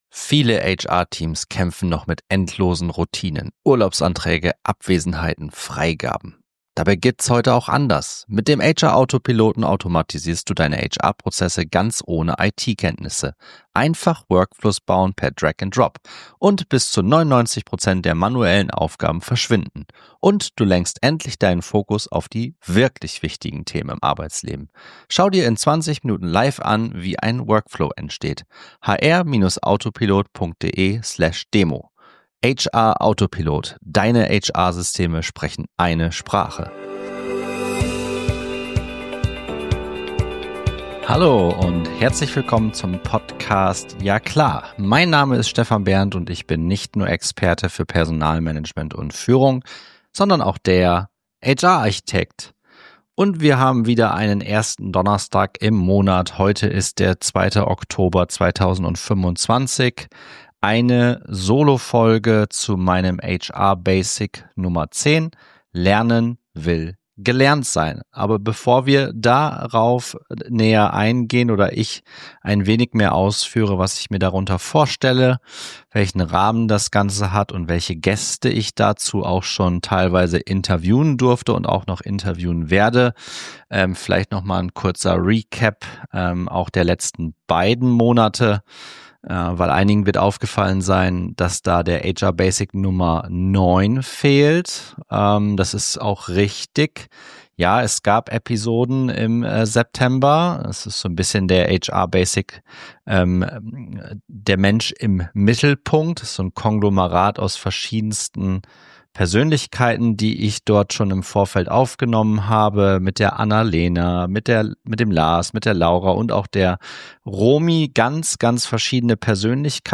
In dieser Solofolge des Ja klaHR!